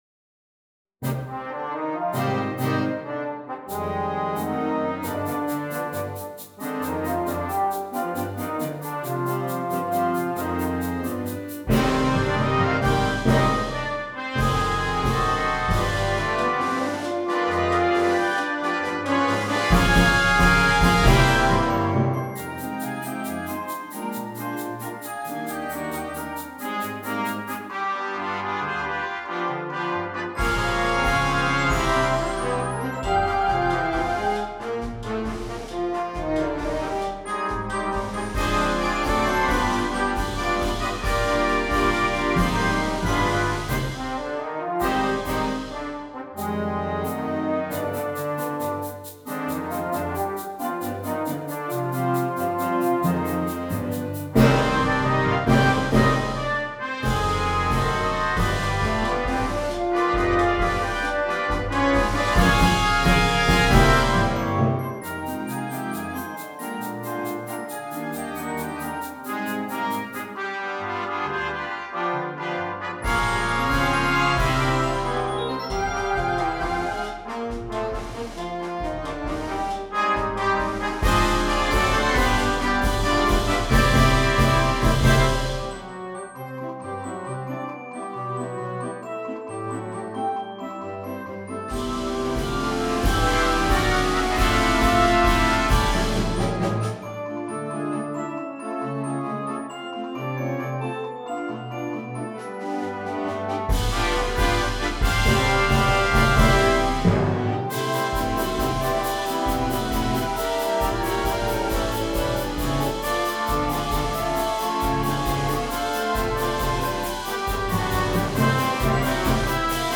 • Piccolo
• Flauta
• Oboe
• Fagot
• Clarinete Bajo
• Saxofón Barítono
• Tuba
• Glockenspiel
• Platillos